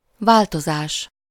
Ääntäminen
IPA: [tʁɑ̃s.fɔʁ.ma.sjɔ̃]